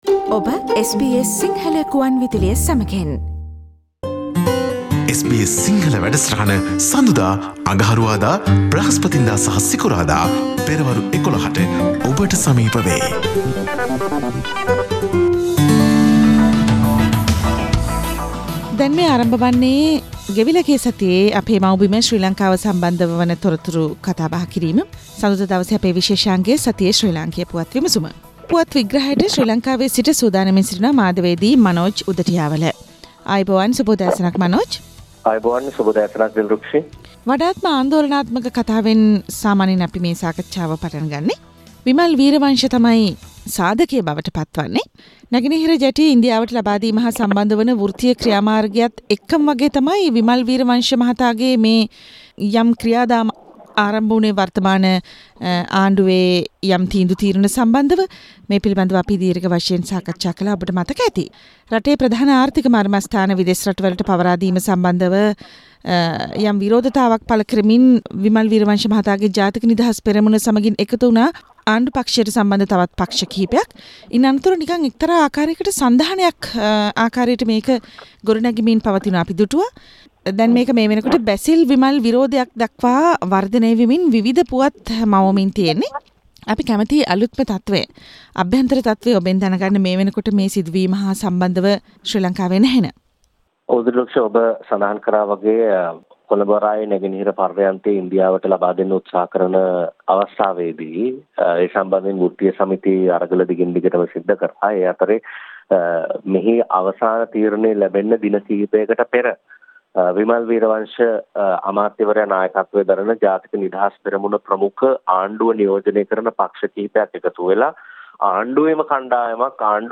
Comprehensive weekly political wrap from Sri Lanka